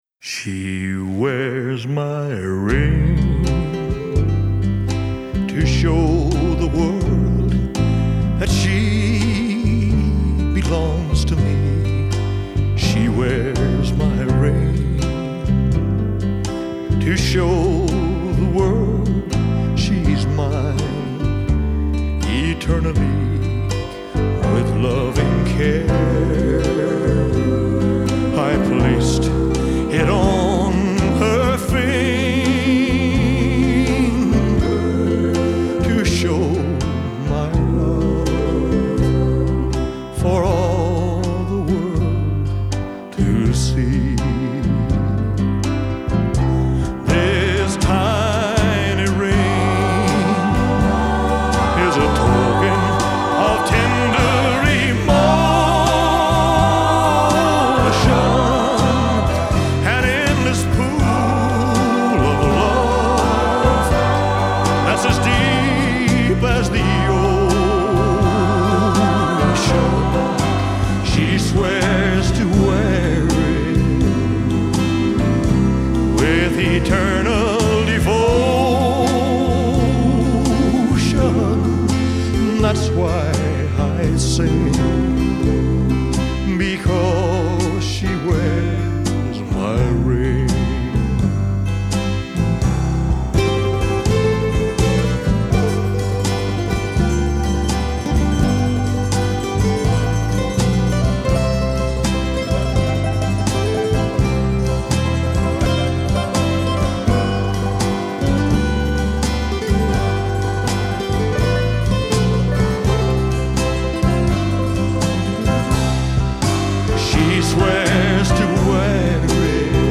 Genre : Classic, Pop